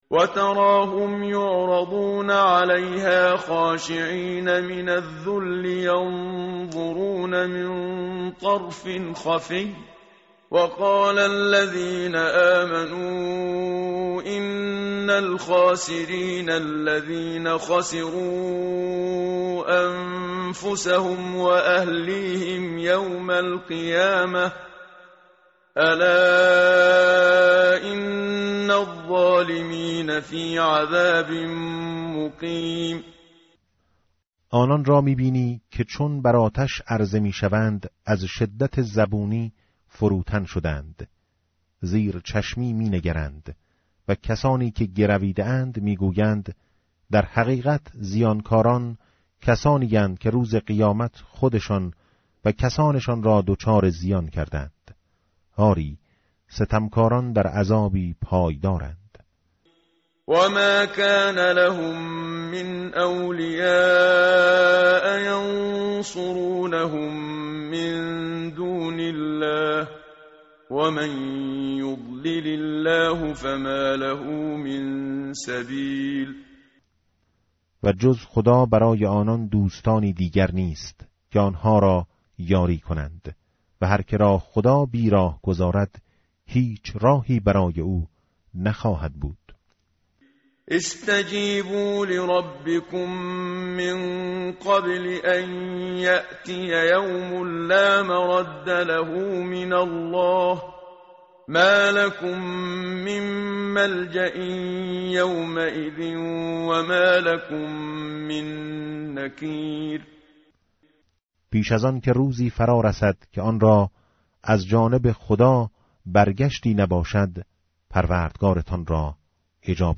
متن قرآن همراه باتلاوت قرآن و ترجمه
tartil_menshavi va tarjome_Page_488.mp3